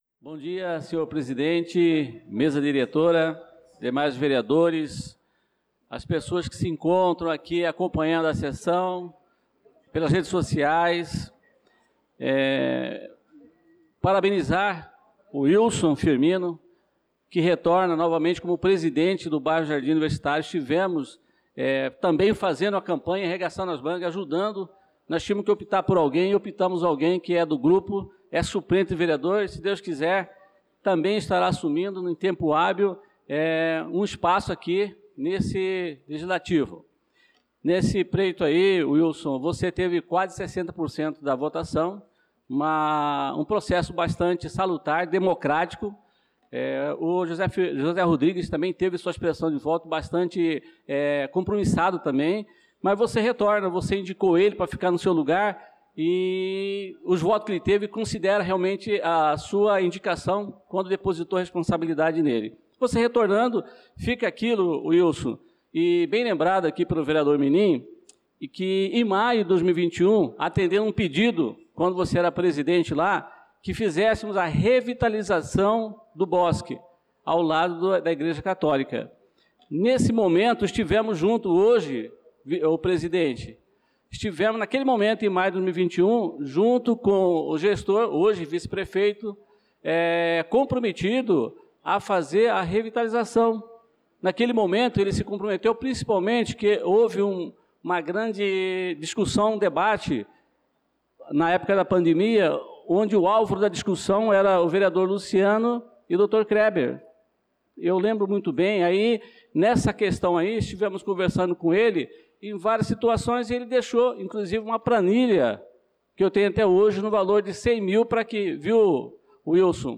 Pronunciamento do vereador na Sessão Ordinária do dia 28/04/2025